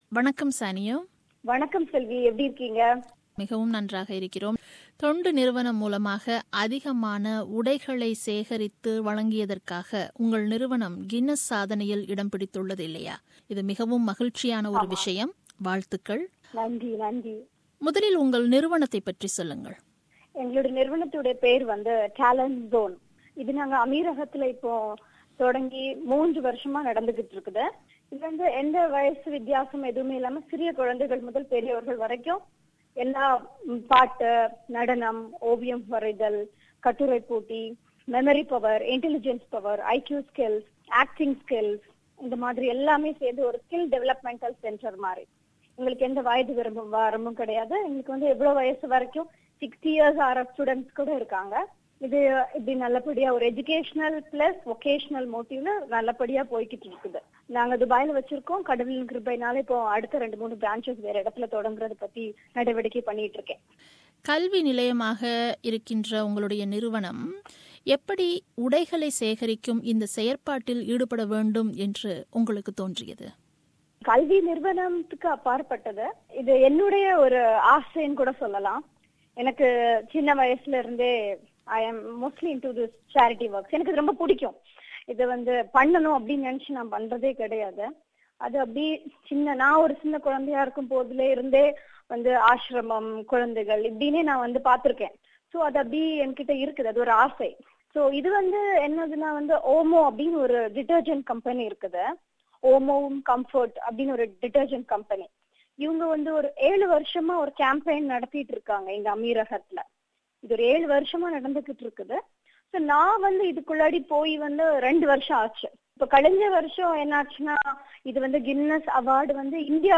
ஒரு சந்திப்பு.